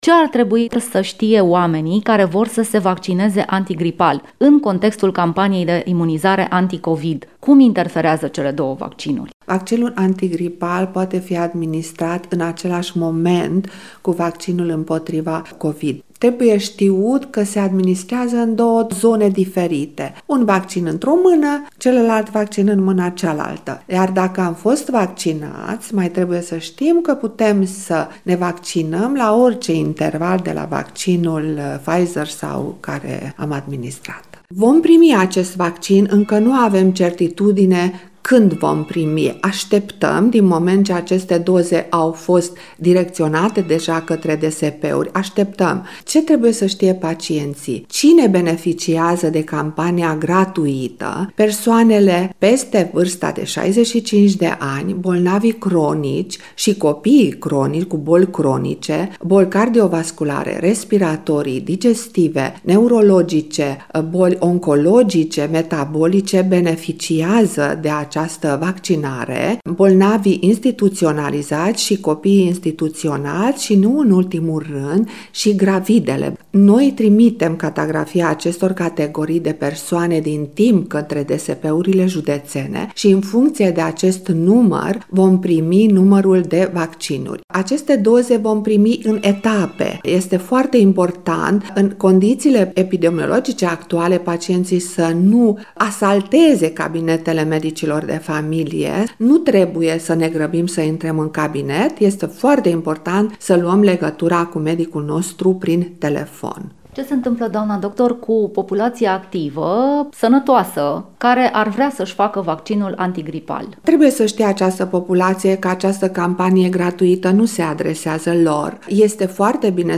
Interviu - Radio Constanţa